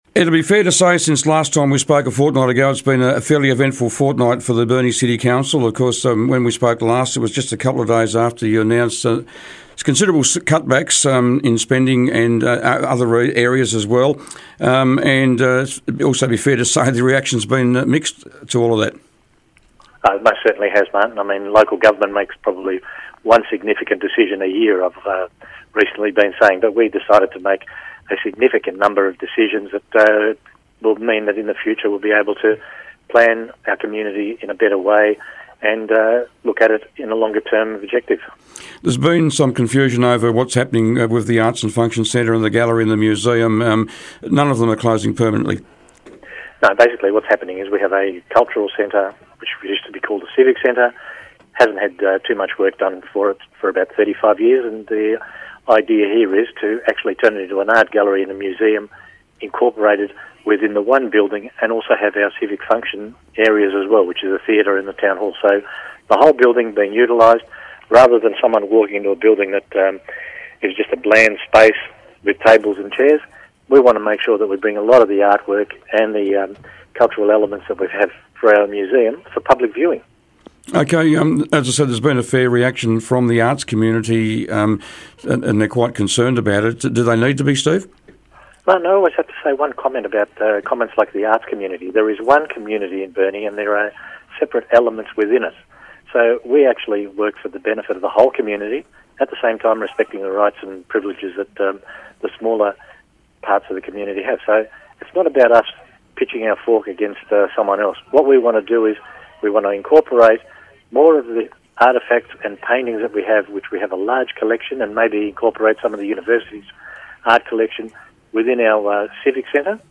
Burnie Mayor Steve Kons was today's Mayor on the Air.